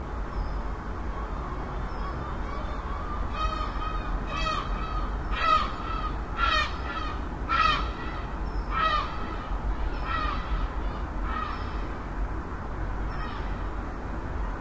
描述：各种鸟，主要是海鸥。用索尼M10在我的公寓窗户上录制。
Tag: 城市 海鸥 乌鸦